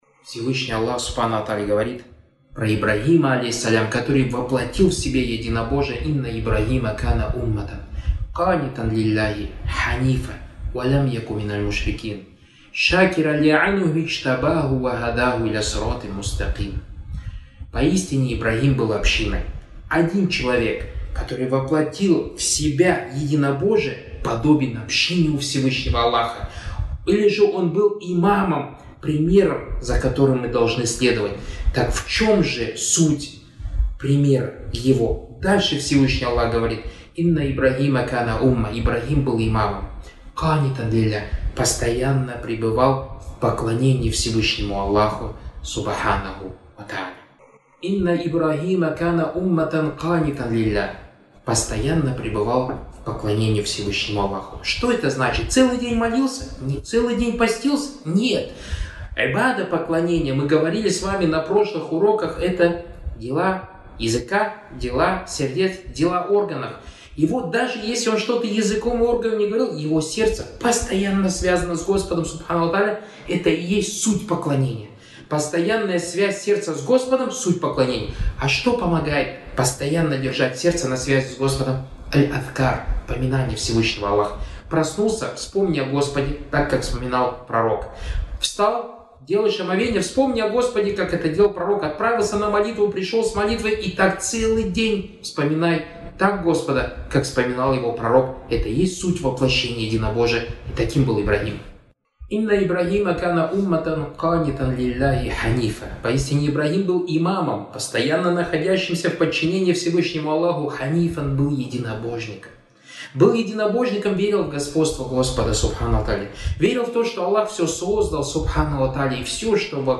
«Право Аллаха на Его рабов» — небольшой курс лекций о единобожии (таухид), что и является тем самым великим правом, которое Всевышний Создатель миров имеет на Своих рабов.